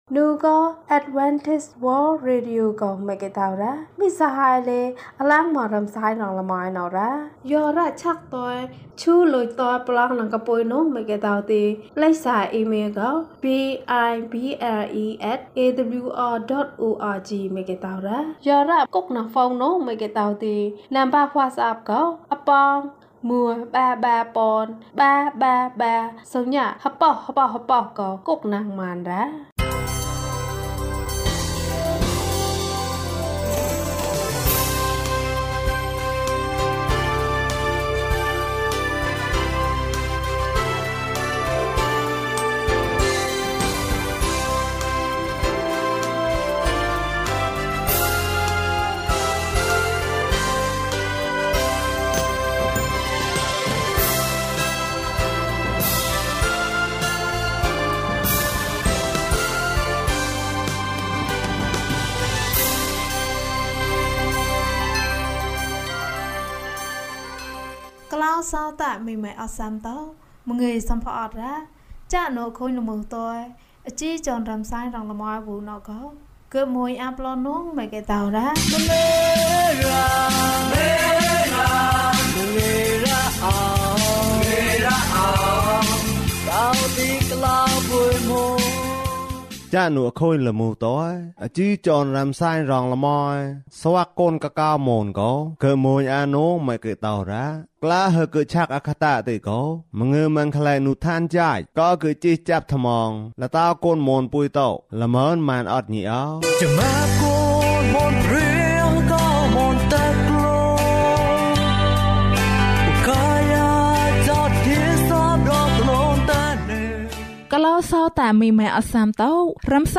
ခရစ်တော်ထံသို့ ခြေလှမ်း။၃၈ ကျန်းမာခြင်းအကြောင်းအရာ။ ဓမ္မသီချင်း။ တရားဒေသနာ။